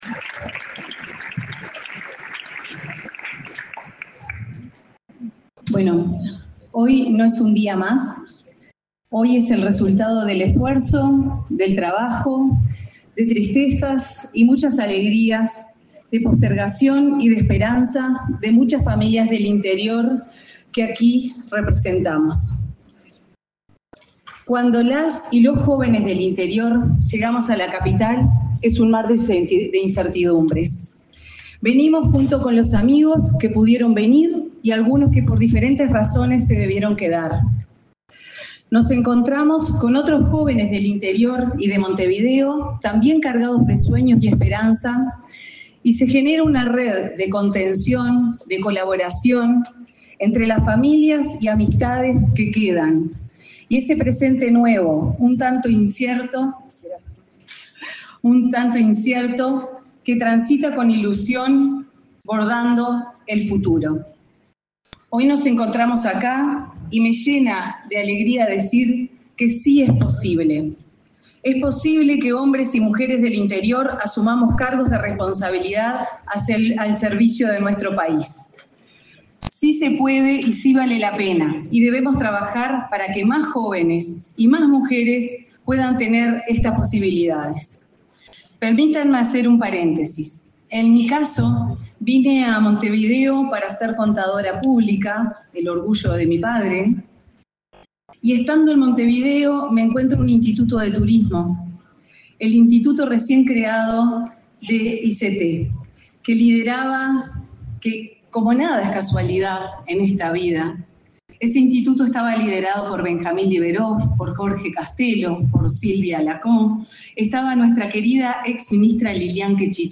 Palabras de la subsecretaria de Turismo, Ana Claudia Caram
Palabras de la subsecretaria de Turismo, Ana Claudia Caram 06/03/2025 Compartir Facebook X Copiar enlace WhatsApp LinkedIn El presidente de la República, profesor Yamandú Orsi, junto a la vicepresidenta, Carolina Cosse, participó, este 6 de marzo, en la ceremonia de asunción de autoridades del Ministerio de Turismo. Además del ministro Pablo Menoni, disertó la subsecretaria Ana Claudia Caram.